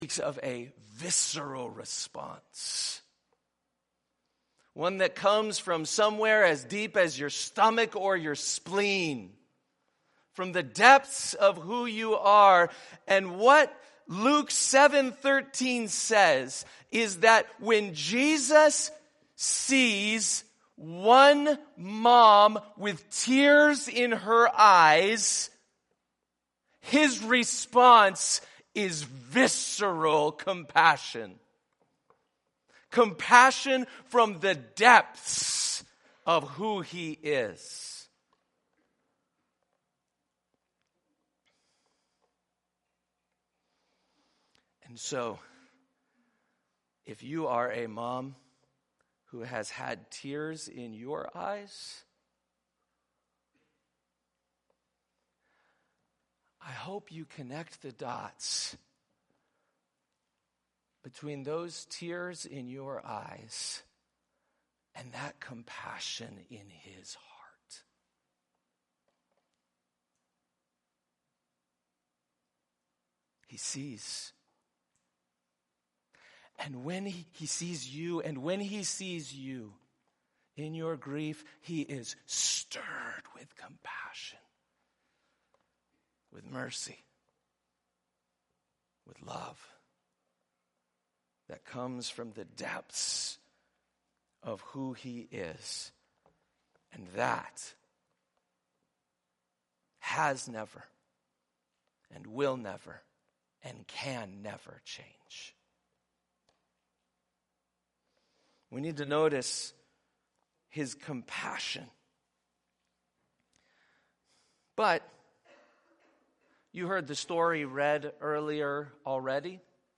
Part 2 in series (only 2nd half was recorded)